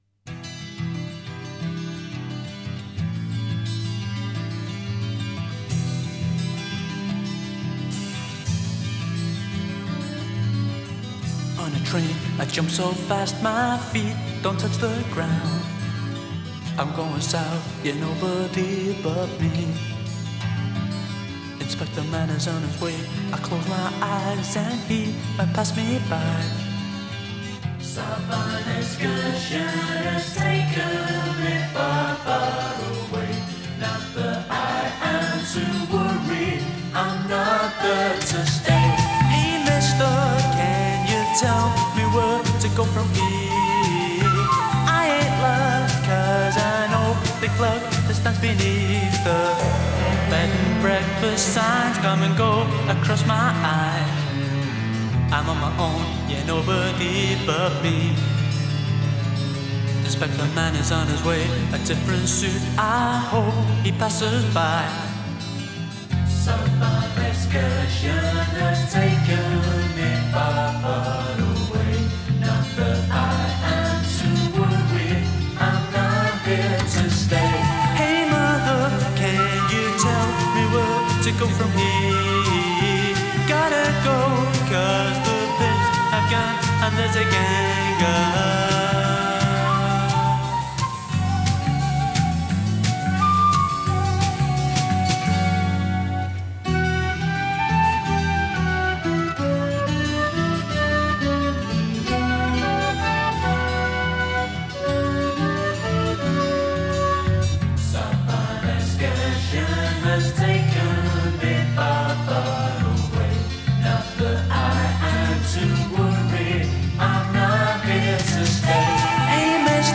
Genre: Indie, Lo-Fi, New Wave